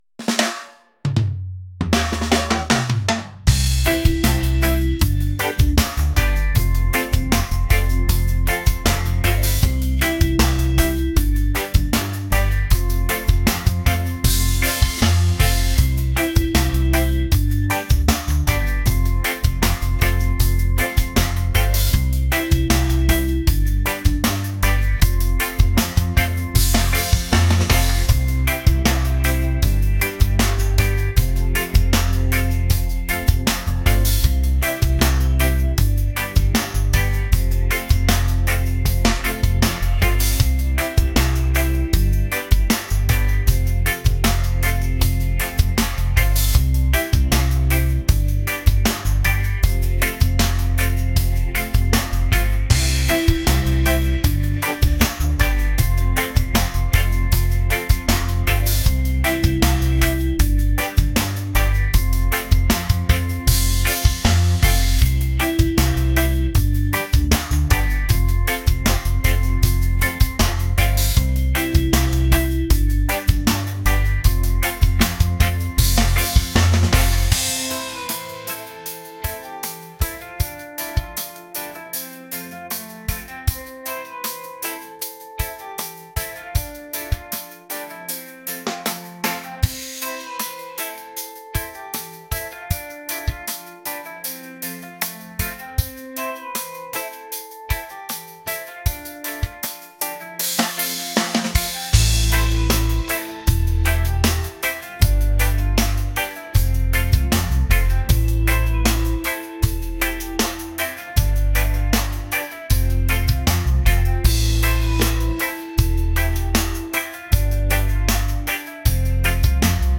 laid-back | reggae | island